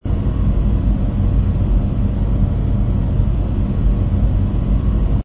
ambience_bar_ground_smaller.wav